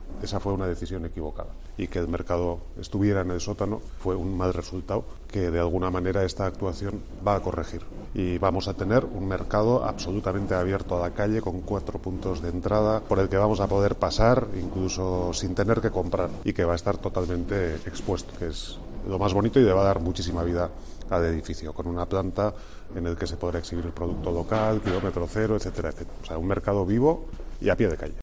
Eneko Goia, alcalde de San Sebastián